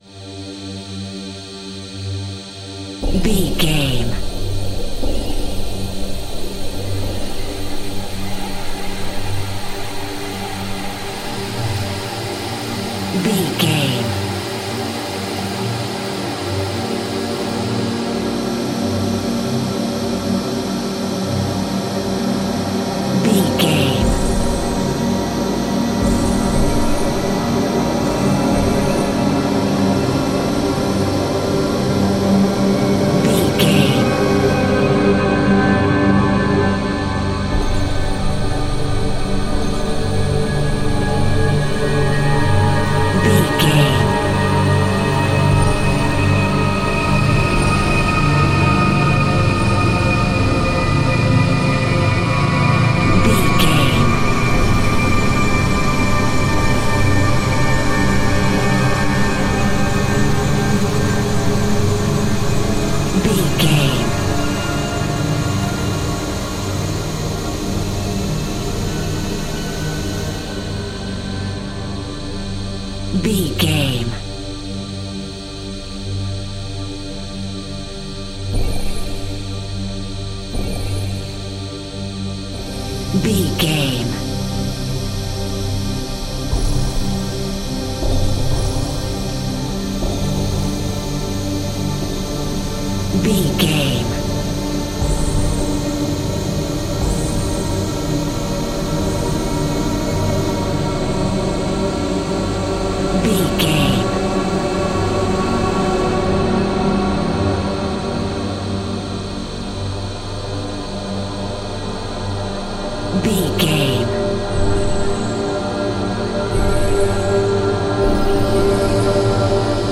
Haunting Atmosphere Music Cue.
In-crescendo
Aeolian/Minor
Slow
scary
ominous
dark
suspense
eerie
synthesiser
horror
Synth Pads
atmospheres